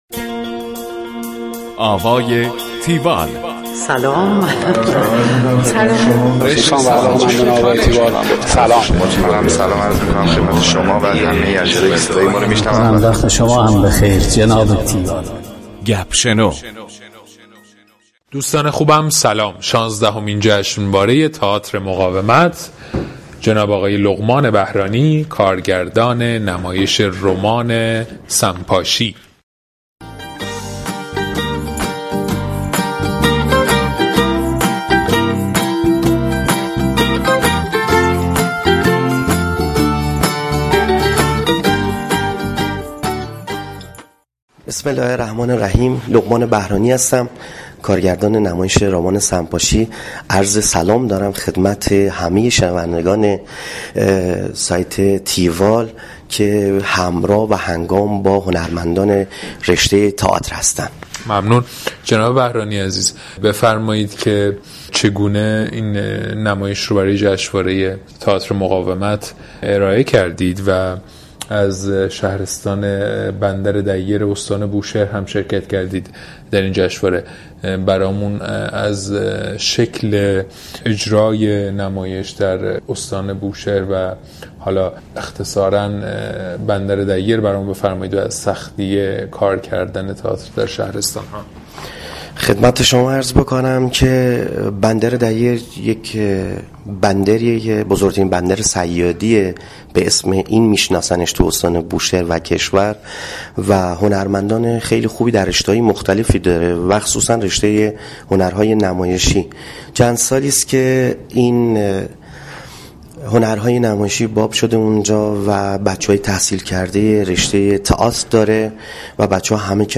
گفتگو کننده